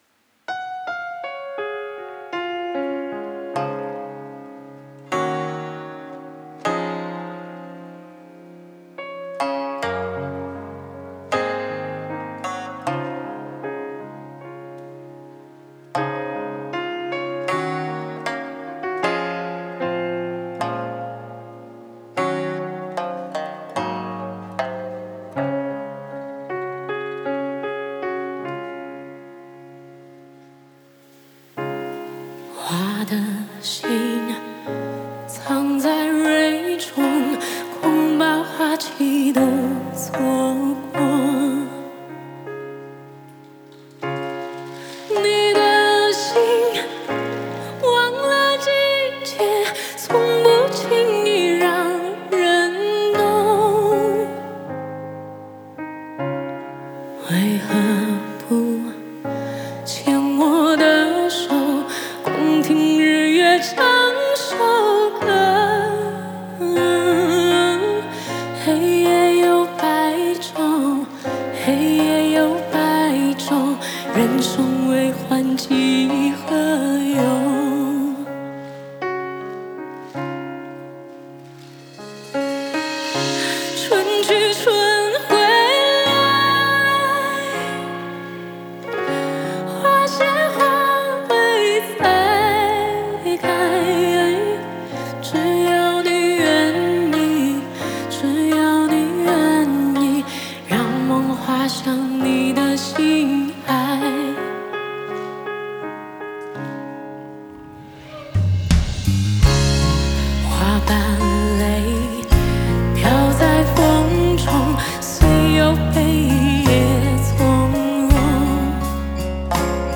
三弦